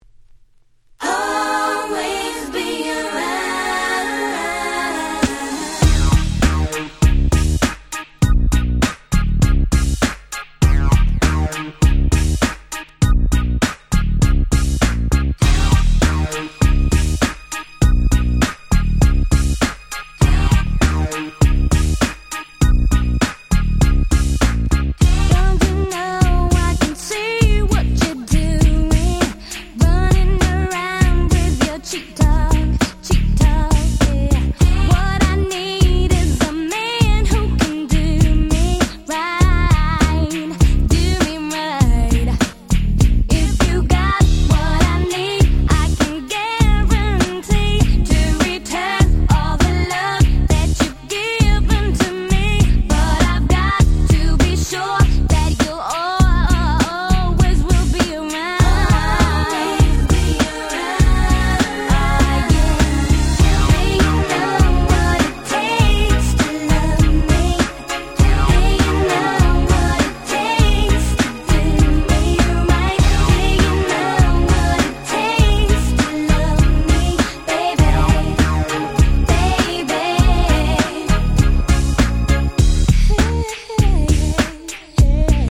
97' Super Hit R&B !!
スウェーデンの女性シンガー。